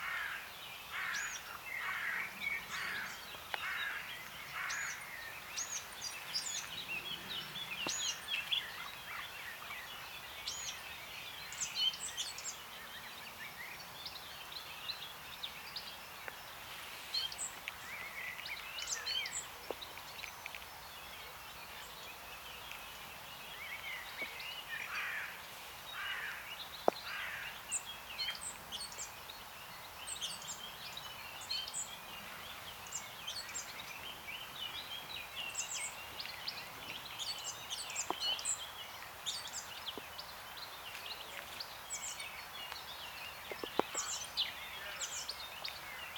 Data resource Xeno-canto - Bird sounds from around the world